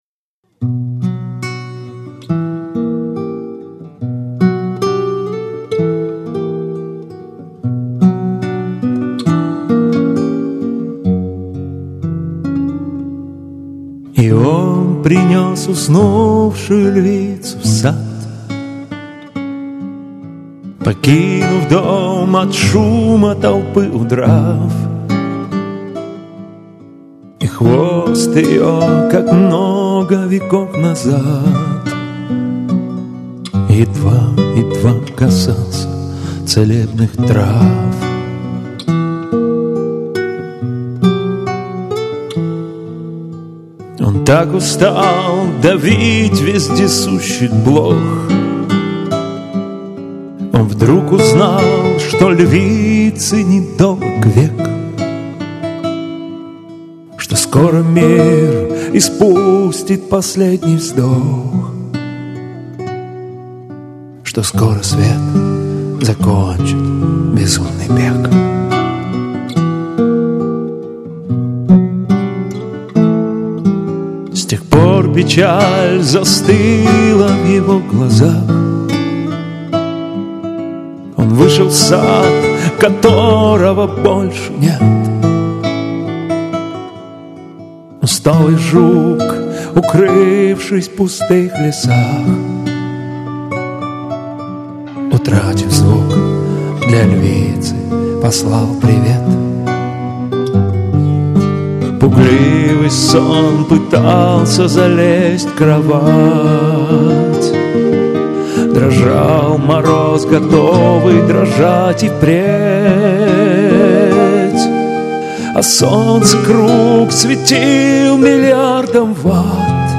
Дуэт